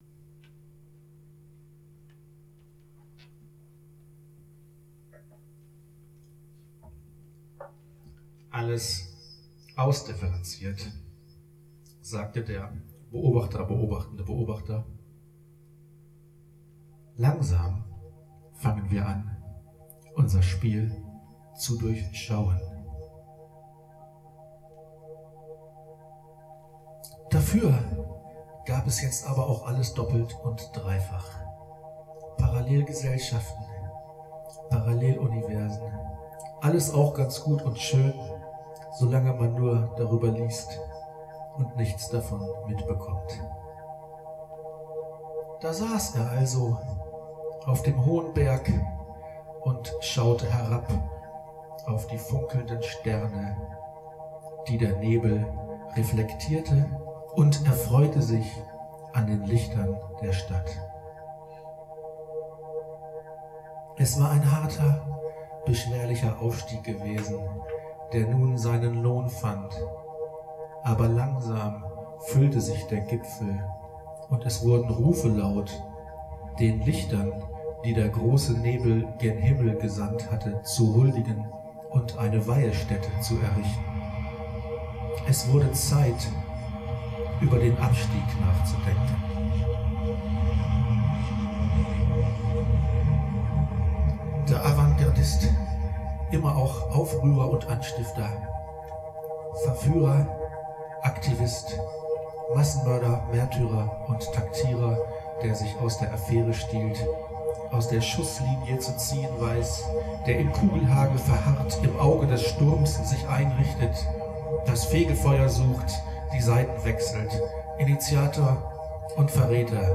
various acoustic and electronic sound devices